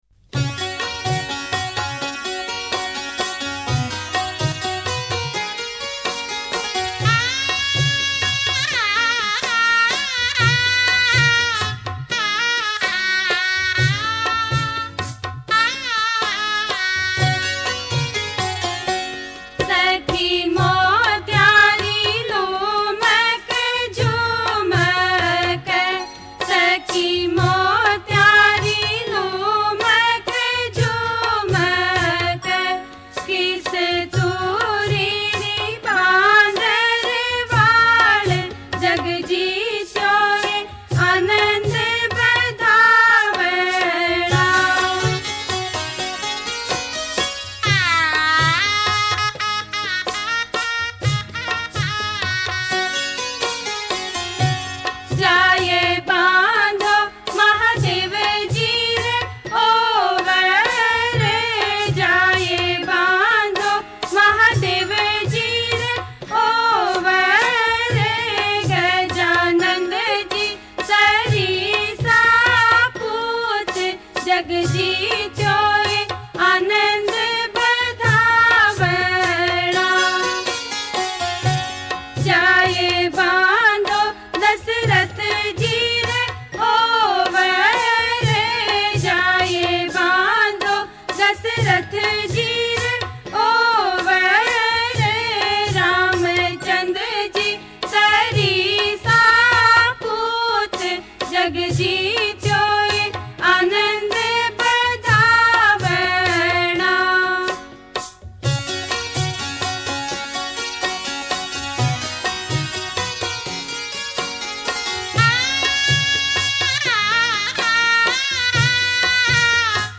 [Folk]